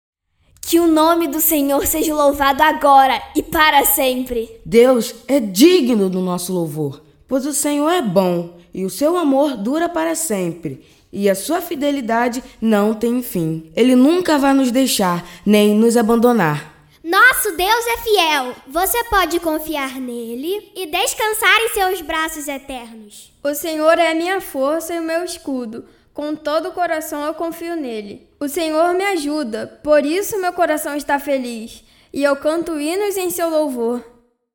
03 - Narração 02